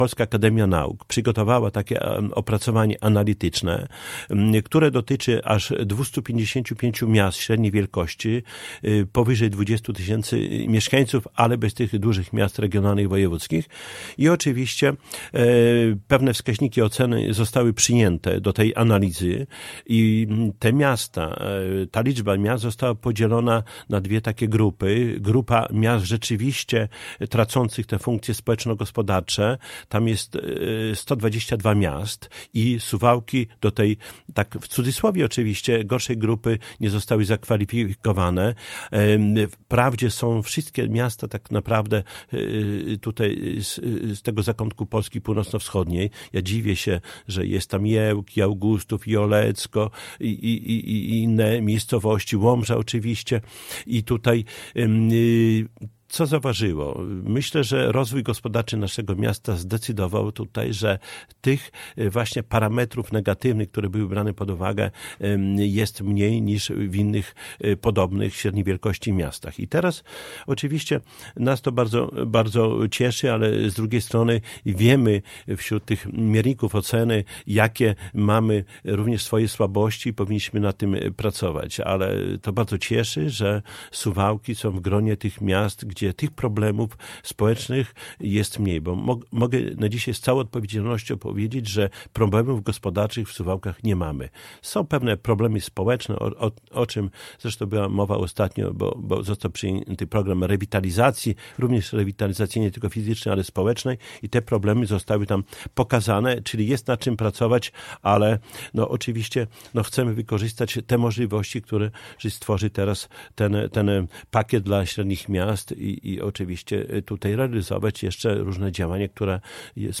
O szczegółach mówił na antenie Radia 5 Czesław Renkiewicz, Prezydent Suwałk.